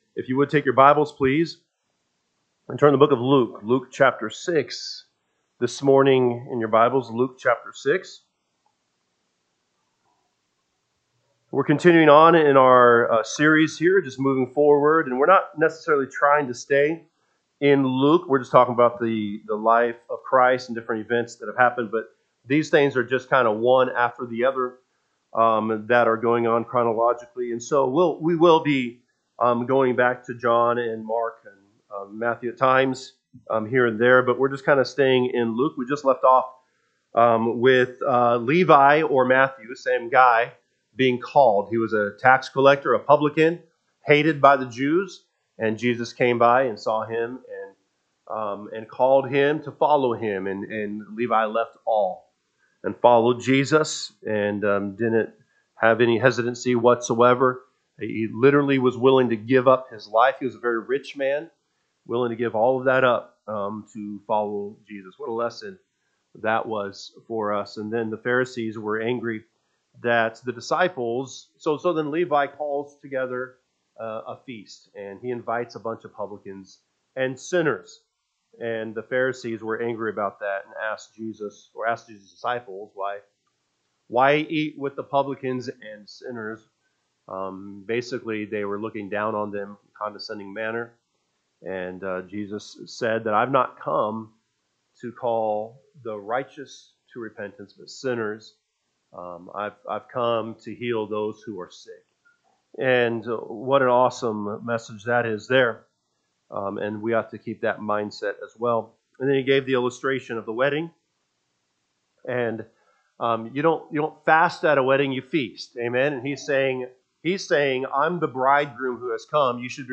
October 26, 2025 am Service Luke 6:1-11 (KJB) 6 And it came to pass on the second sabbath after the first, that he went through the corn fields; and his disciples plucked the ears of corn, and…